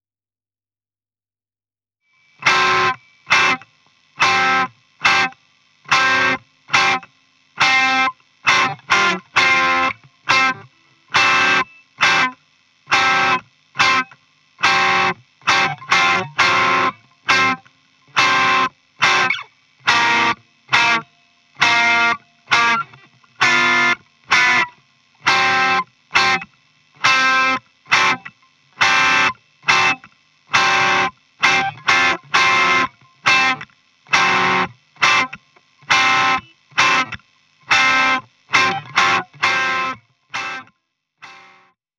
Dann klingt das schon sehr ähnlich dem RS127, aber etwas smoother.